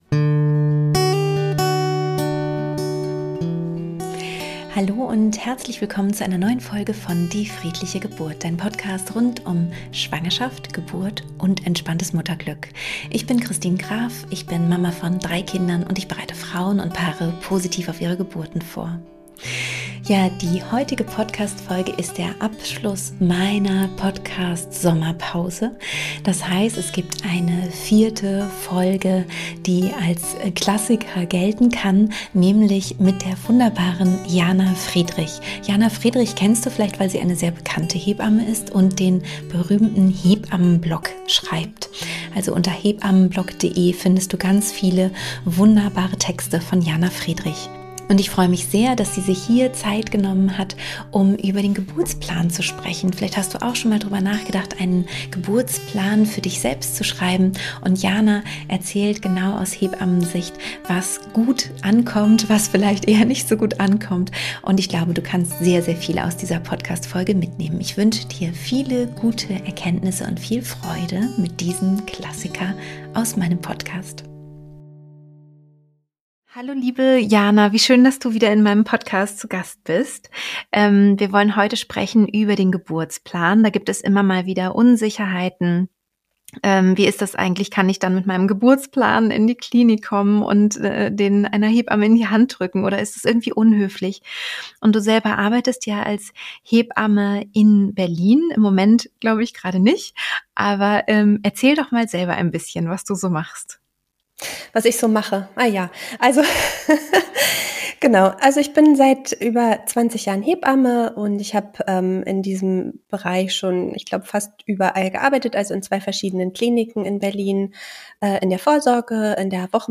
1 351 – ELTERNSEIN – Wie hole ich mir Hilfe in der Krise? – Interview